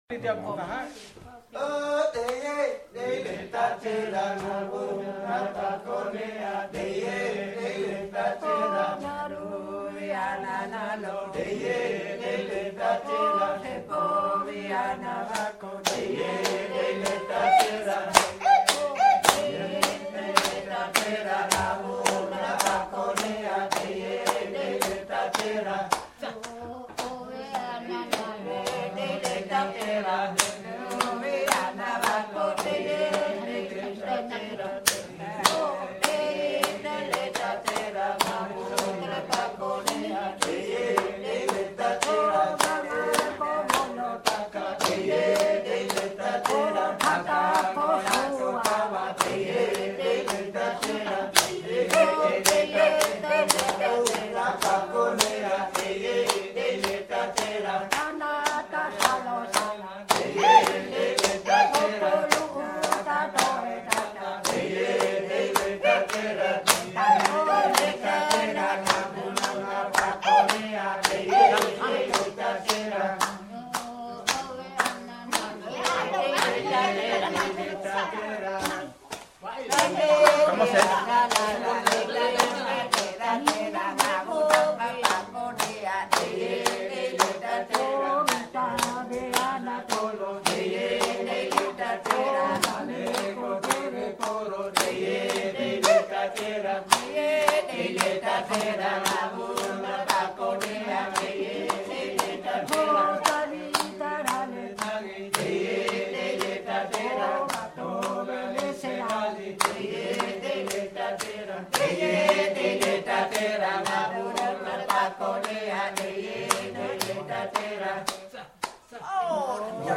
orang-ngada-acappela-group-in-kampung-borani.mp3